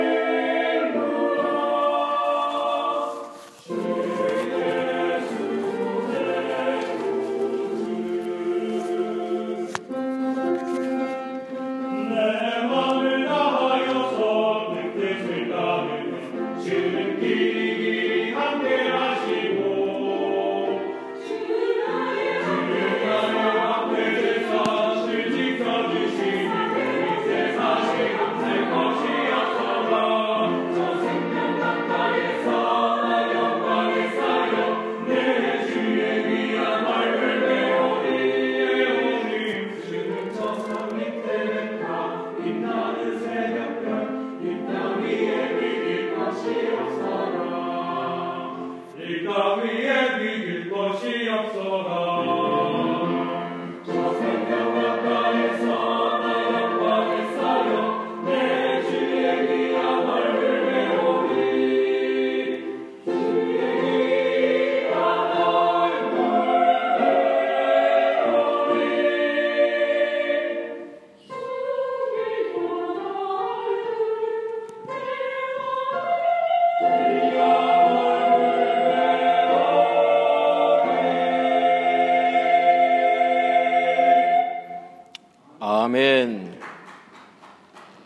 2월 7일 주일 찬양대(주는 저 산밑의 백합화, 머레이곡) Posted on February 8, 2016 by webmaster 찬양 2월 7일 주일 찬양대 ‘주는 저 산밑의 백합’ by James Rumey Murray This entry was posted in 찬양대 Choir & Praise Team .